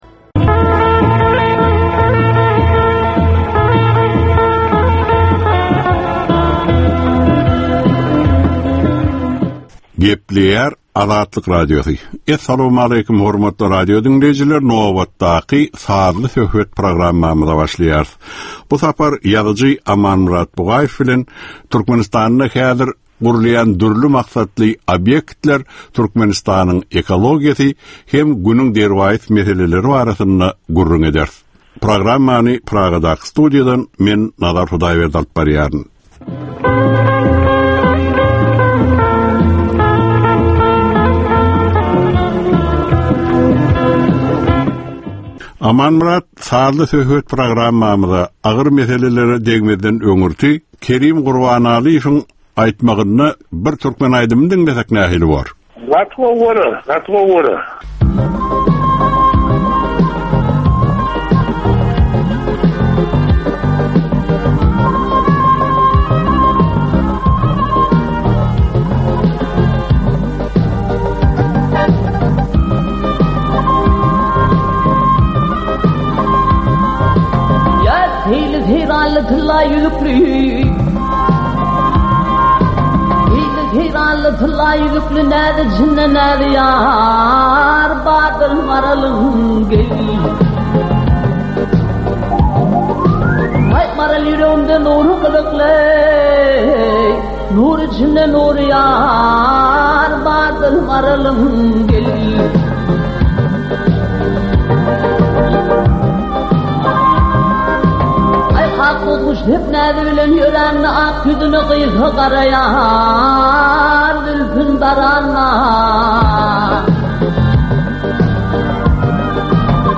Türkmeniň käbir aktual meseleleri barada 30 minutlyk sazly-informasion programma.